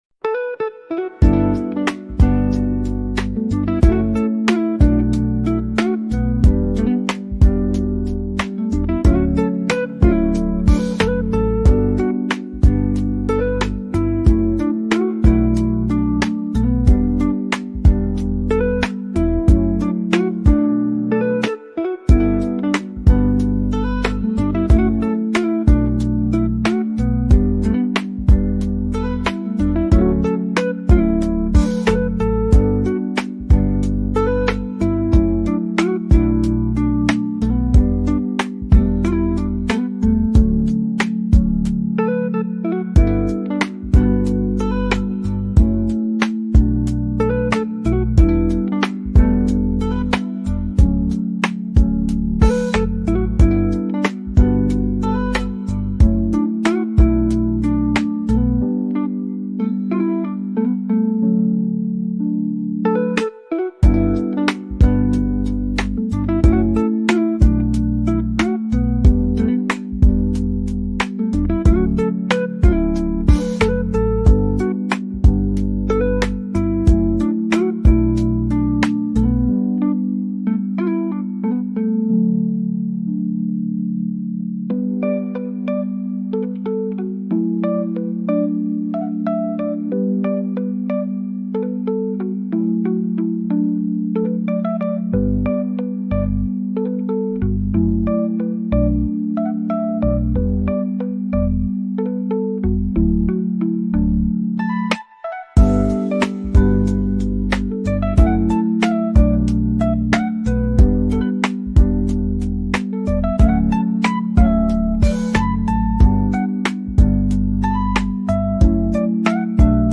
your ultimate destination for calming vibes, chill beats
lo-fi music